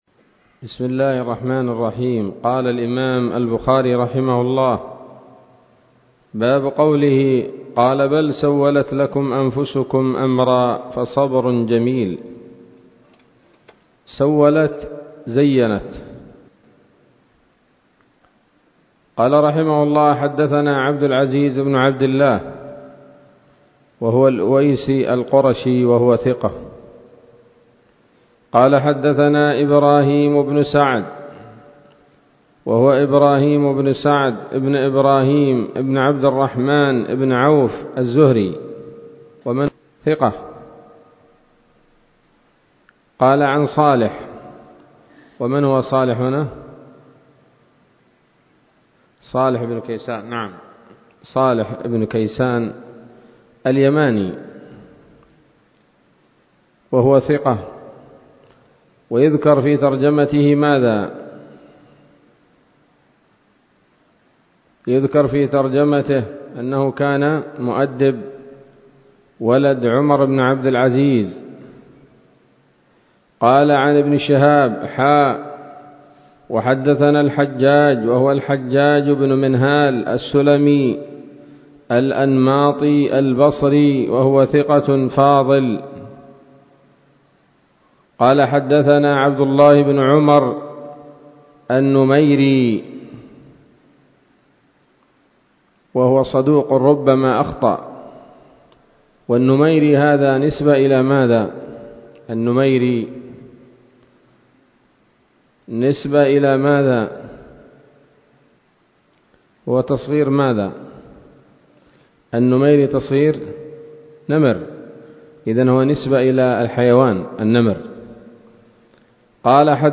الدرس الأربعون بعد المائة من كتاب التفسير من صحيح الإمام البخاري